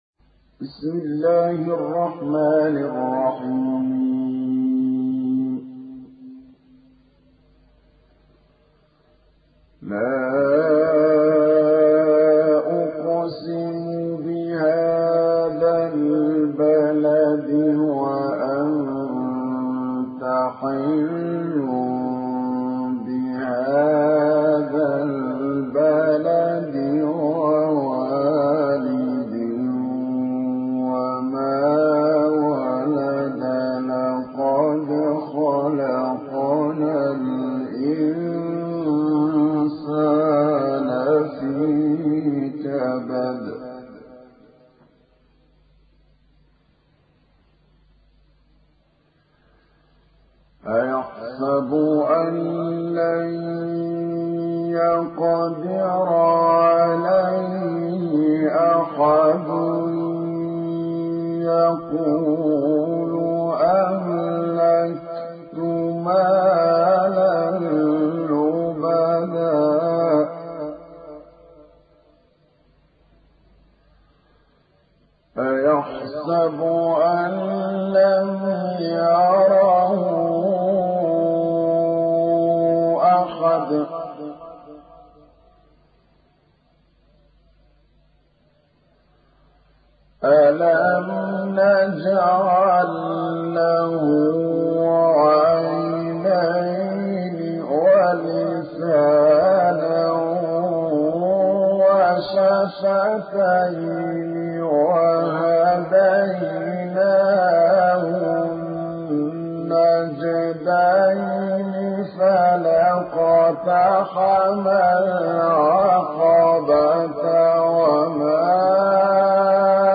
Récitation par Mohamed At Tablawi